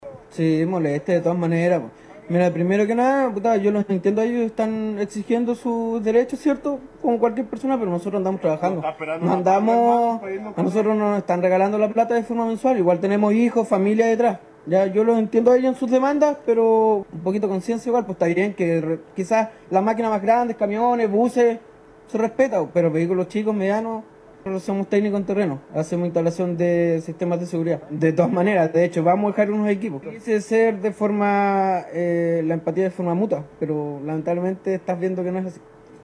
Para automovilistas que están detenidos en el sector no pudiendo circular en sus actividades diarias como lo expresaron dos trabajadores en instalación de equipos de seguridad.